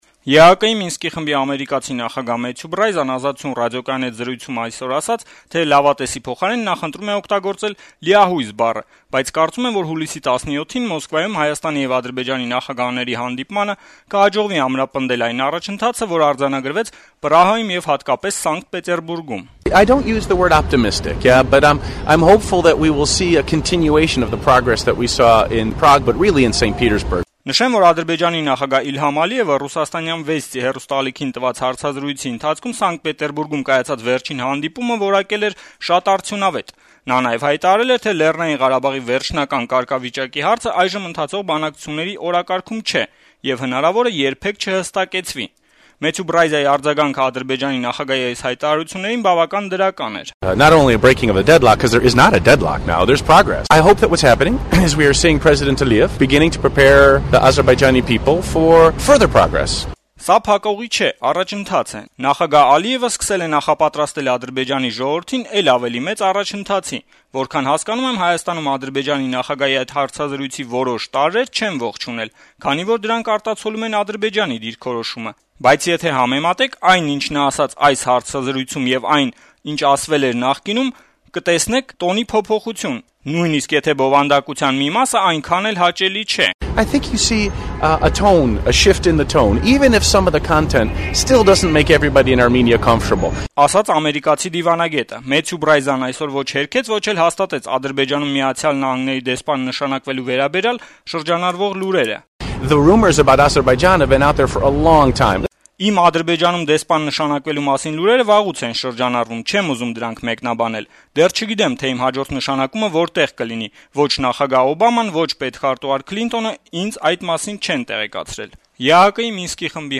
ԵԱՀԿ Մինսկի խմբի համանախագահների ասուլիսը